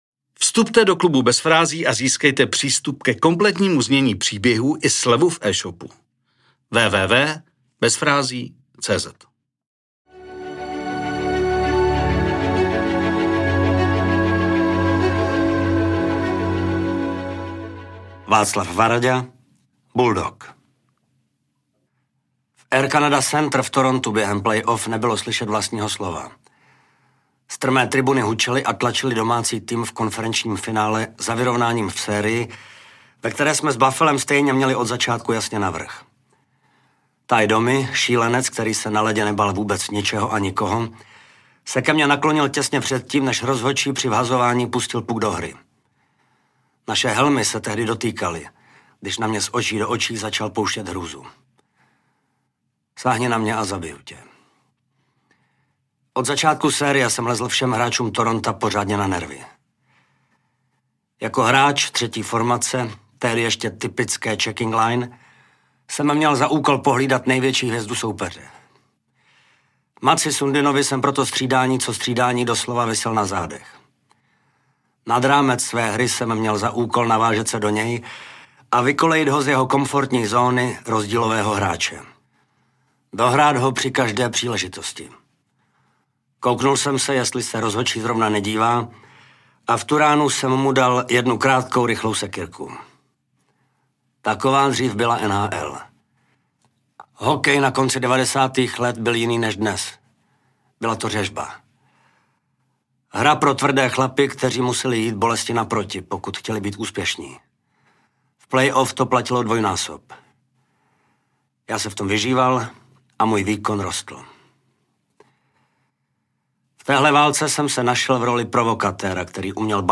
Nádherný příběh trenéra Vítkovic namluvil Martin Hofmann .